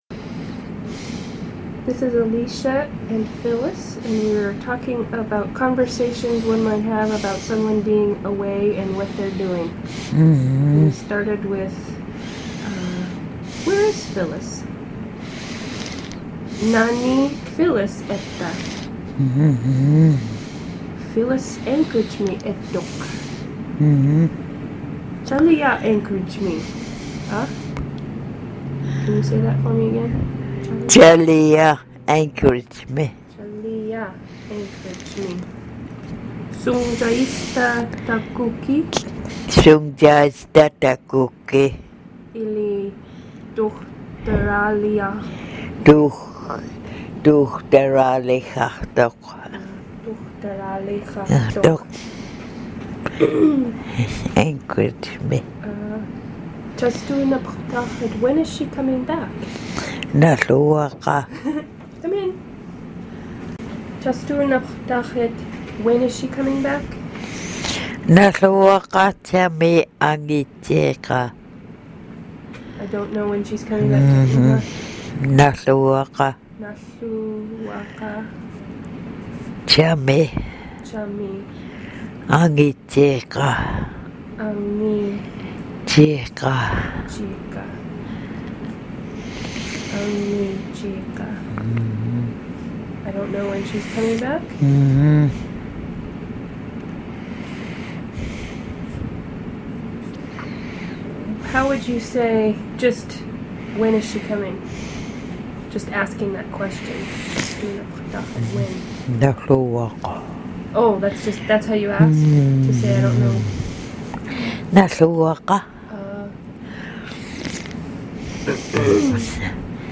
Conversation about being away and what they are doing
Kodiak, Alaska